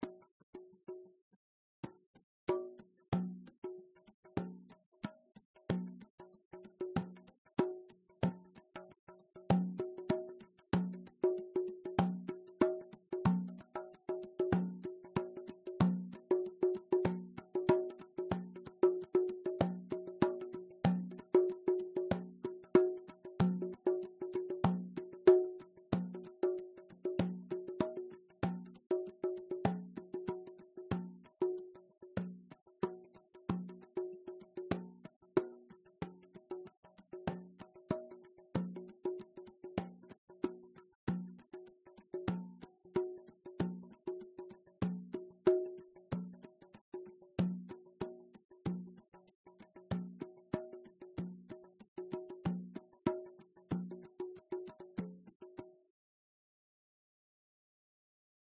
TF醇厚邦戈鼓
描述：一段轻松的邦戈鼓录音。
Tag: 邦戈斯